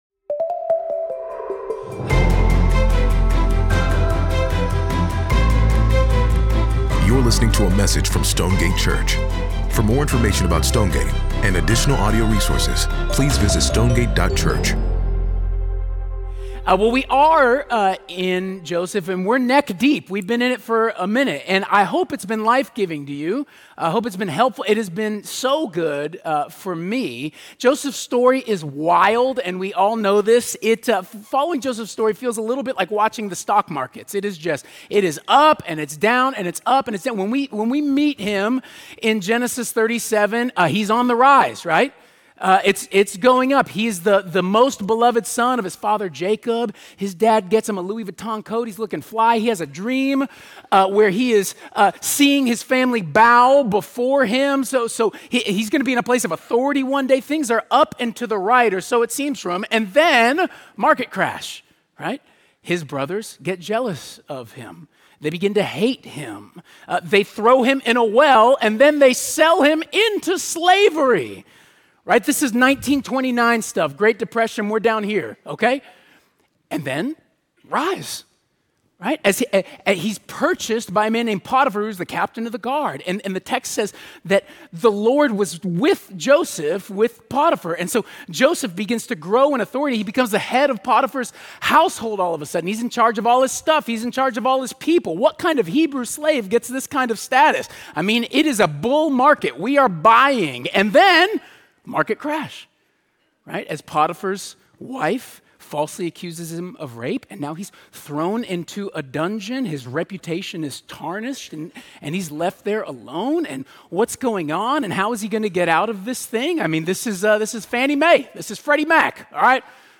10.2 Sermon Podcast.mp3